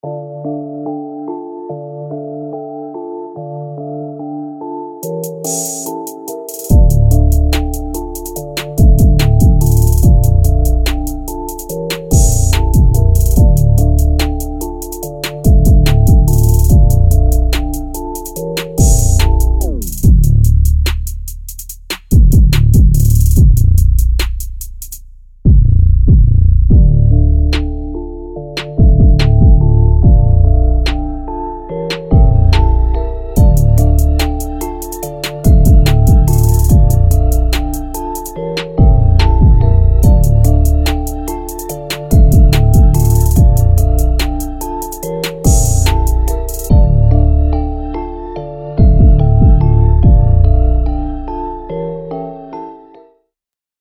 唸るような、サブベース
パンチの効いたパワフルな808スタイルのサブベースを生成します。
• 一貫性のある正確で深みのあるサブベース